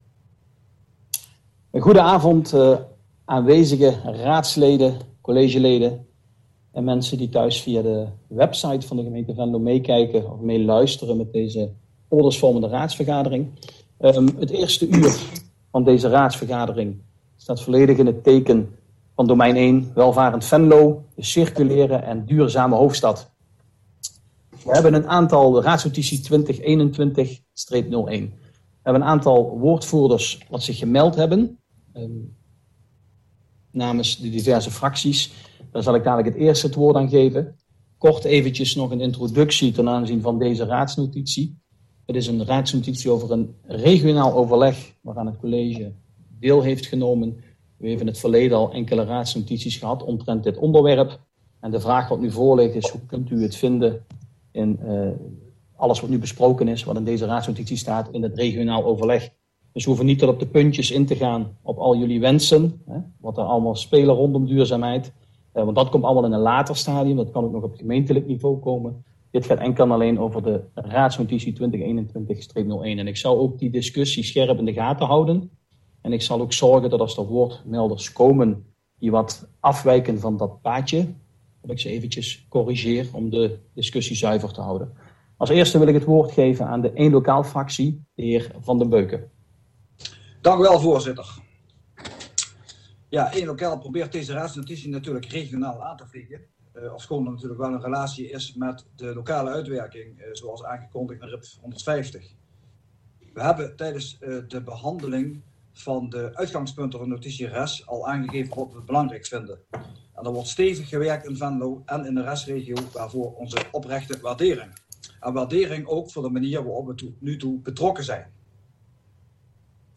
Oordeelsvormende raadsvergadering 10 februari 2021 19:00:00, Gemeente Venlo
Portefeuillehouder: wethouder Marij Pollux Sessievoorzitter: Gerrit Schuurs